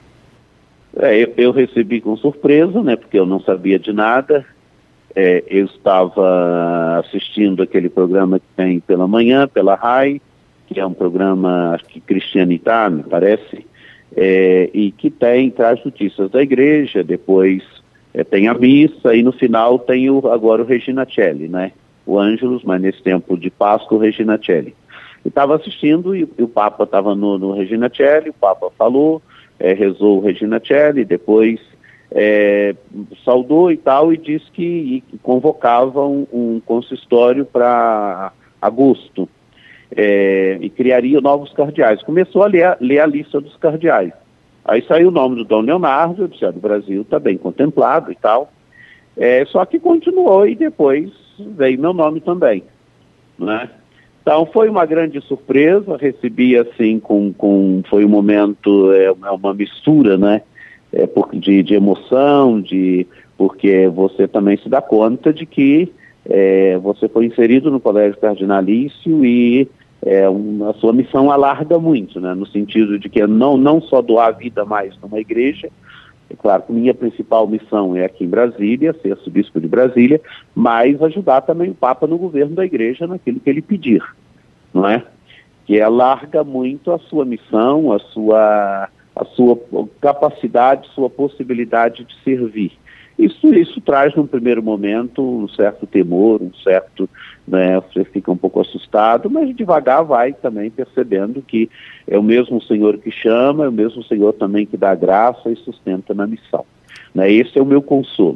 2a-fala-arcebispo-dom-paulo-cezar-1.mp3